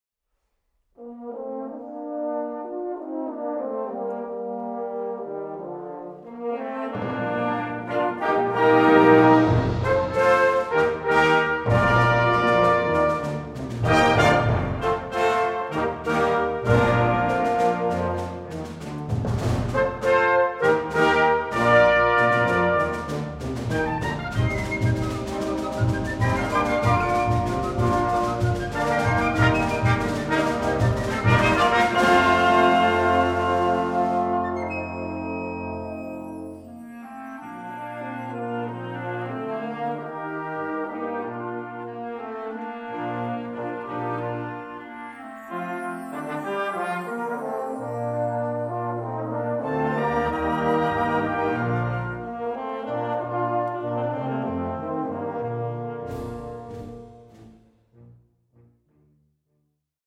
Music for Symphonic Wind Orchestra